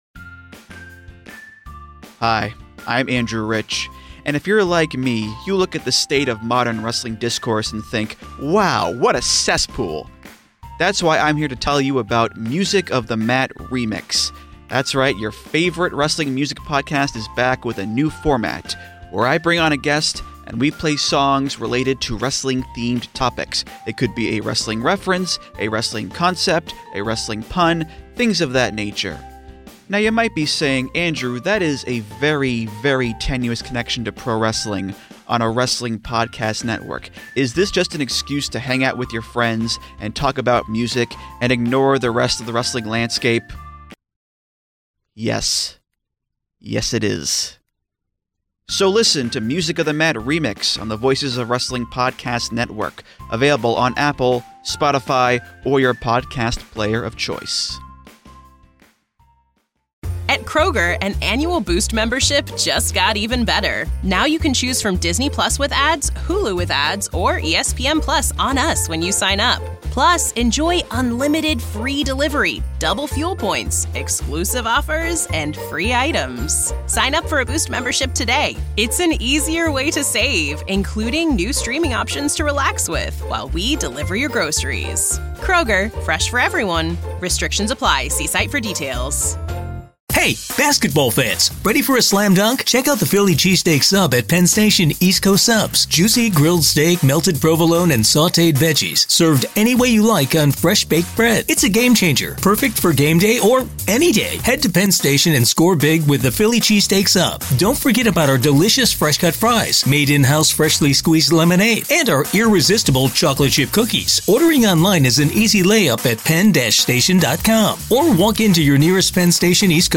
1 the-steve-austin-show-unleashed!: WWE Hall of Famer Mick Foley Part One (SAS CLASSICS) Play Pause 2d ago Play Pause Riproduci in seguito Riproduci in seguito Liste Like Like aggiunto — What happens when two WWE Hall of Famers sit down and start shooting the shit?